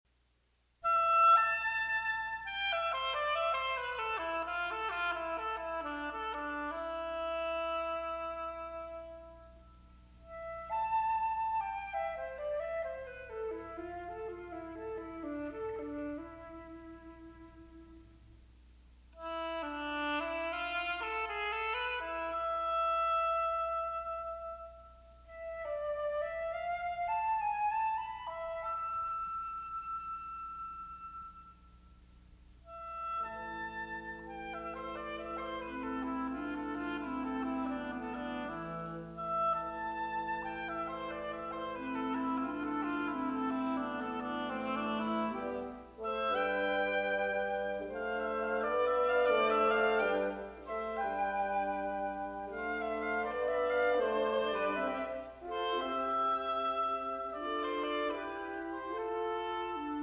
William Kincaid - Flute
Oboe
Clarinet
French Horn
Bassoon